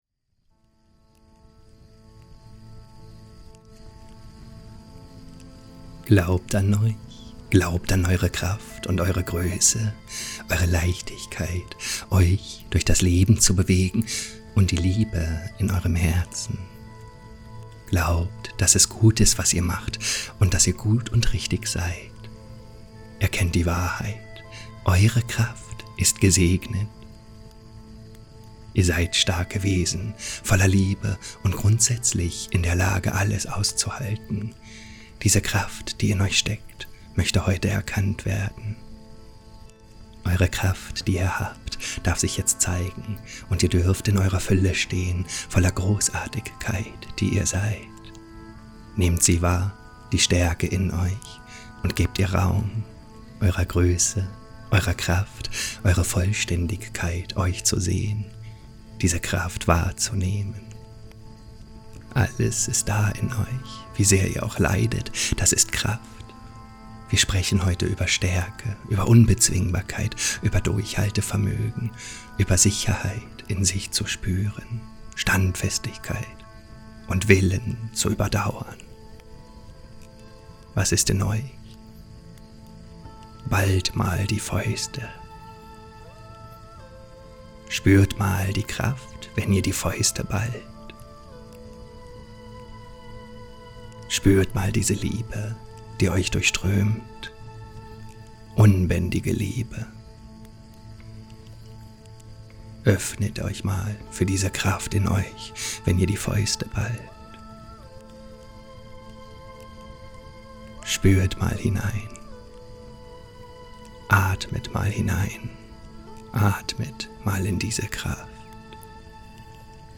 ODIN: „Bereitschaft zu Leiden“ – Channeling & Meditation